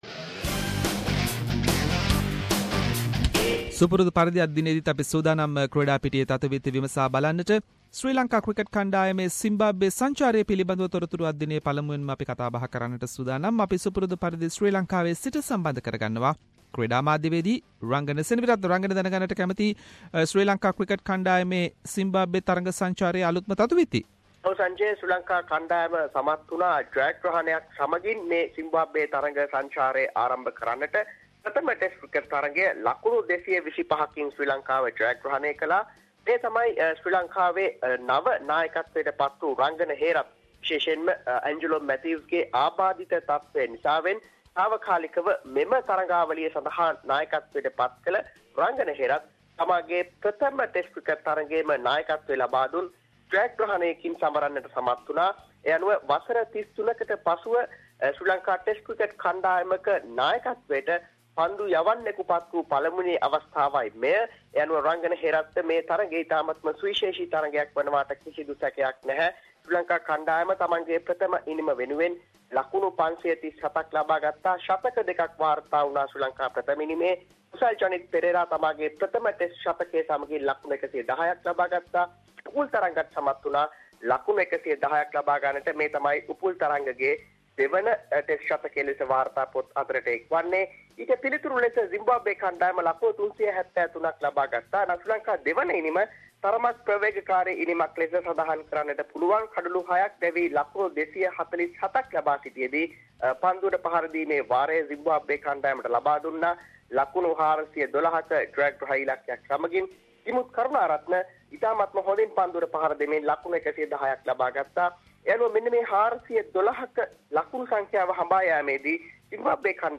In this weeks SBS Sinhalese sports wrap…. Latest from Sri Lanka cricket tour to Zimbabwe, 2016 Melbourne cup, new sports constitution for Sri Lanka and many more local and international sports news.